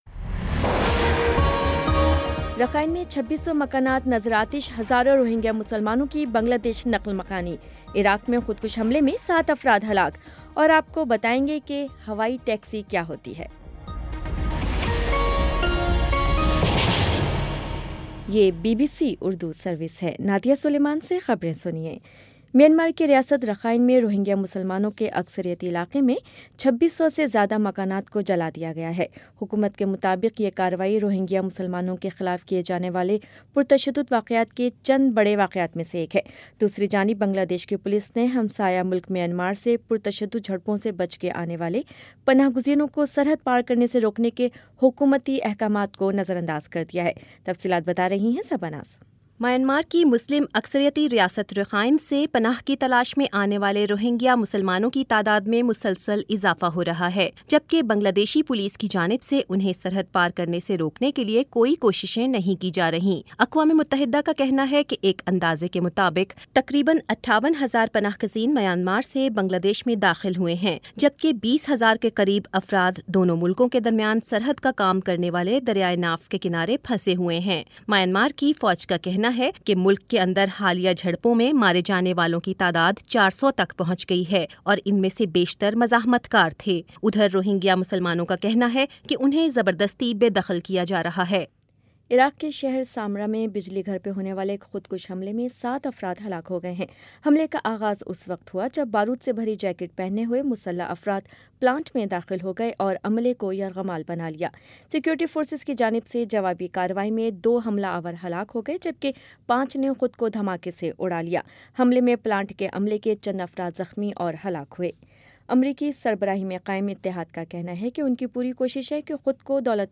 ستمبر 02 : شام چھ بجے کا نیوز بُلیٹن